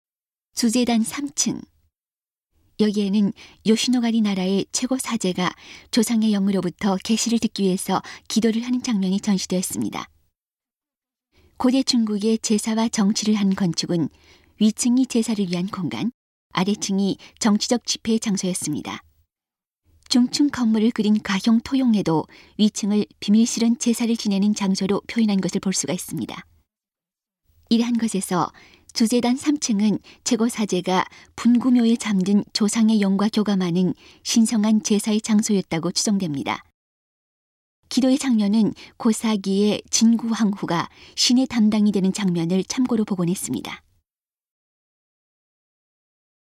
음성 가이드 이전 페이지 다음 페이지 휴대전화 가이드 처음으로 (C)YOSHINOGARI HISTORICAL PARK